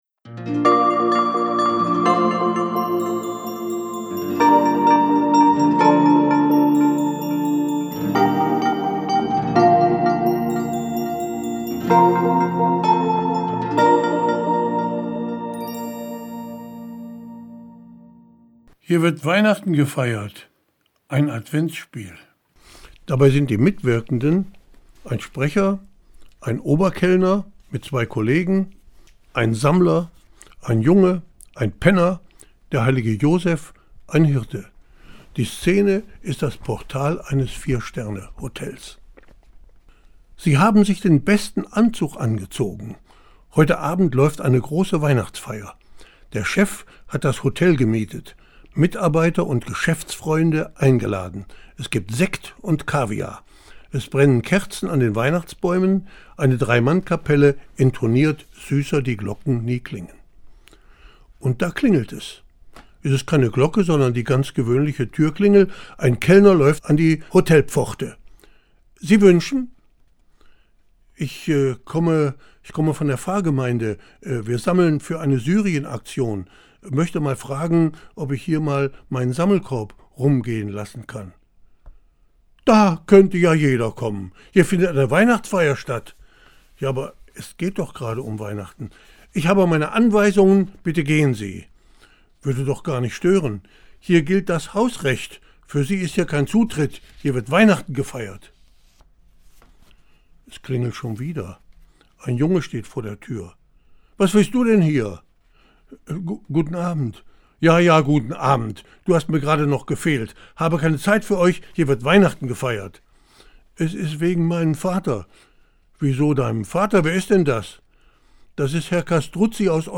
Das Hörbuch zum Buch: Weihnachten berührt alle. Das Fest wird geliebt, ersehnt, kritisiert, verspottet.